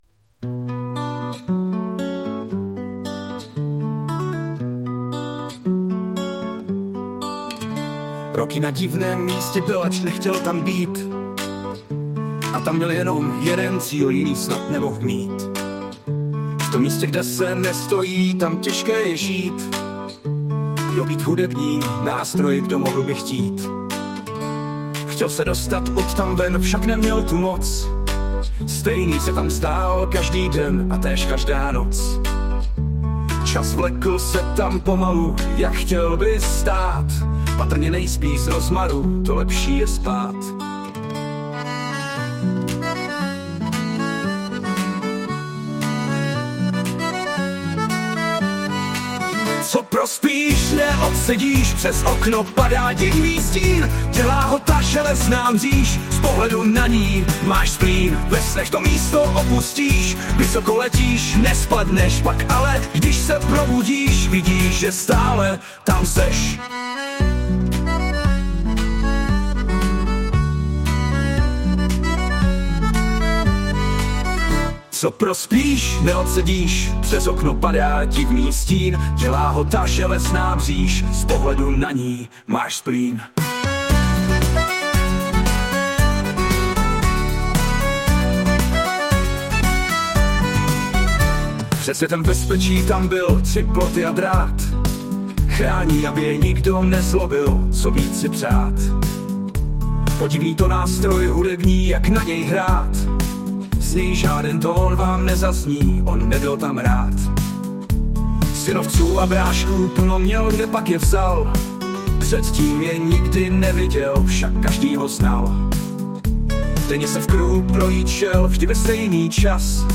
Anotace: Zhudebněno pomocí AI